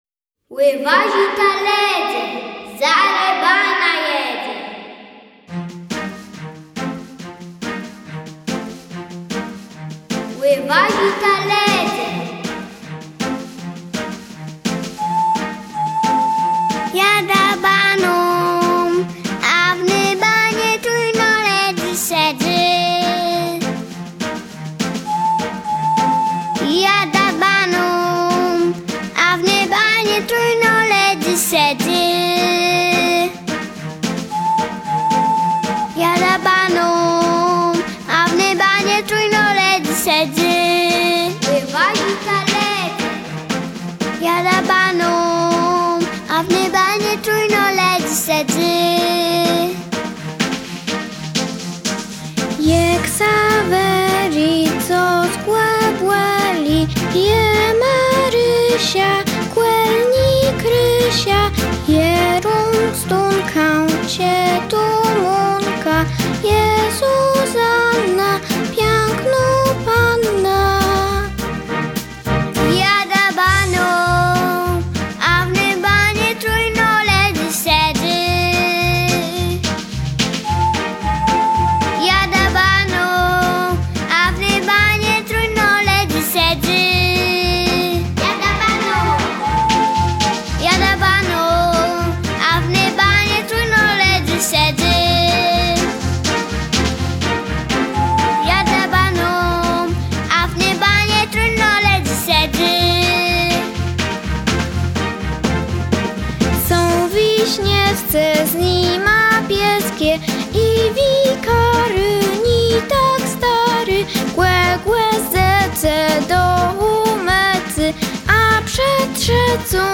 Nagranie wykonania utworu tytuł: Jadã baną , autor: Różni Wykonawcy Odsłuchań/Pobrań 4